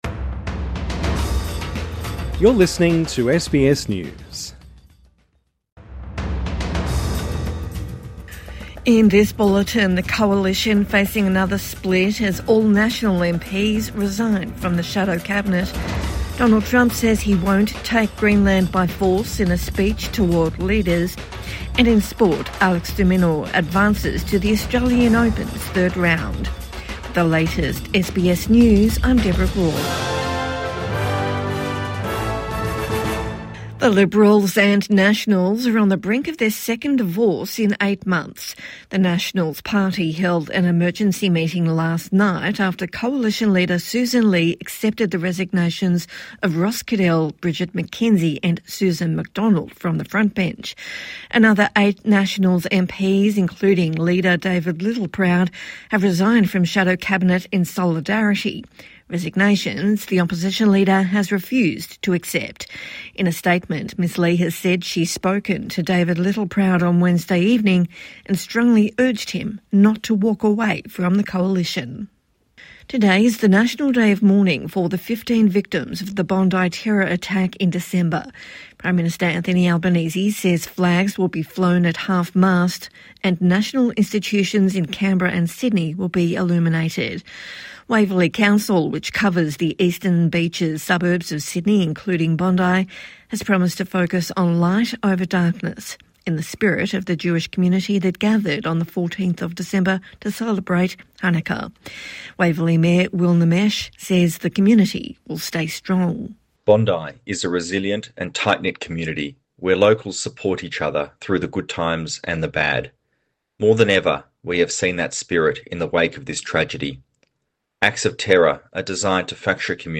Nationals quit the shadow cabinet over hate speech laws | Morning News Bulletin 22 January 2026